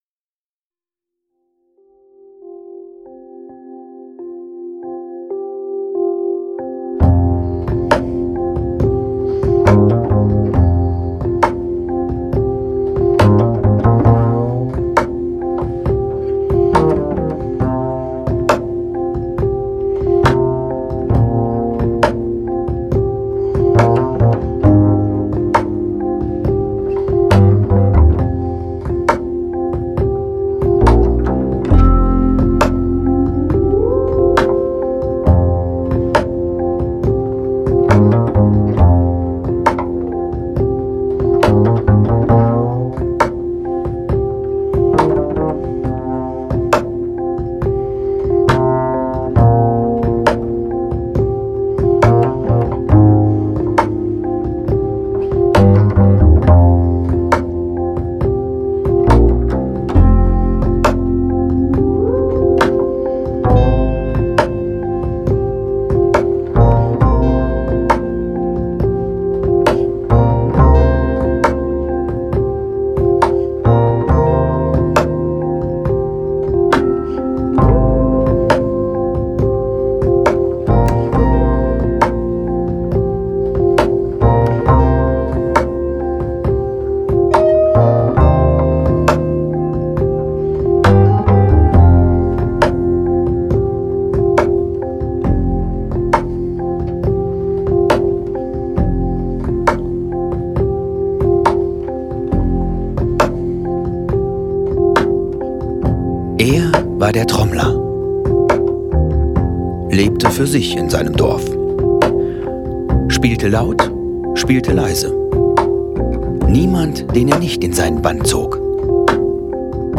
Sprecher: